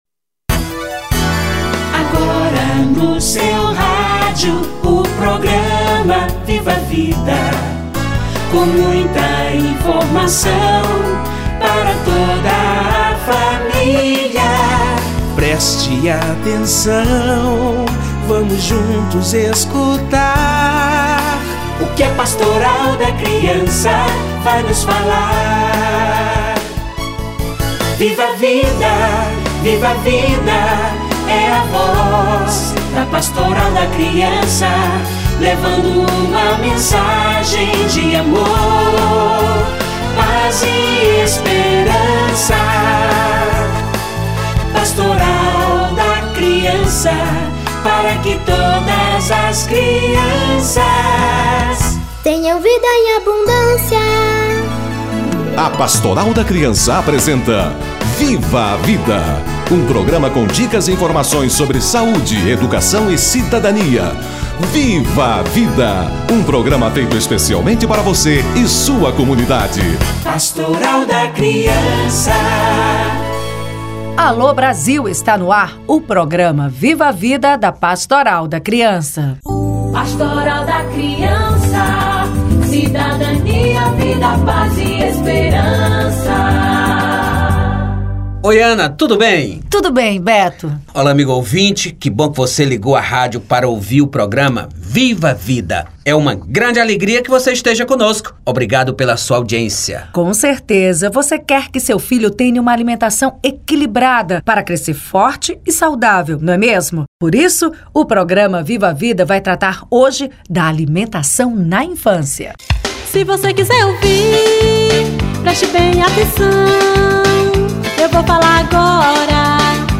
Alimentação na infância - Entrevista